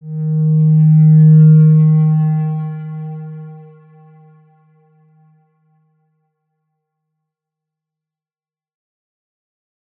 X_Windwistle-D#2-ff.wav